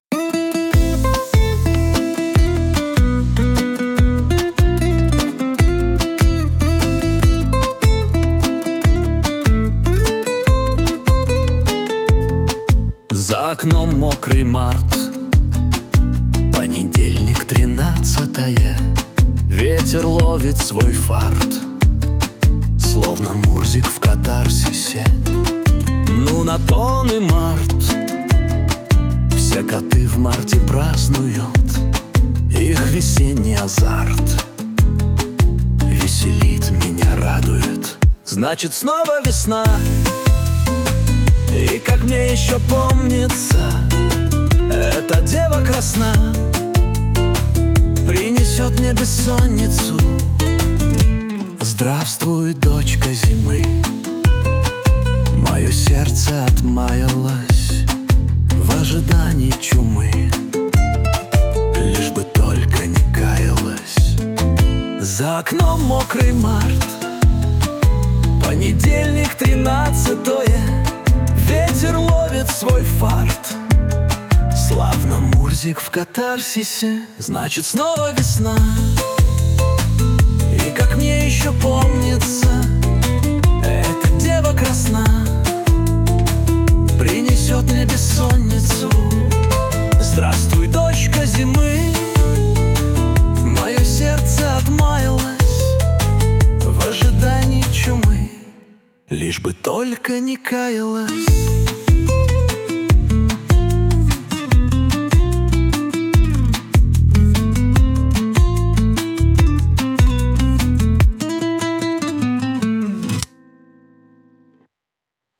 • Аранжировка: Ai
• Жанр: Поп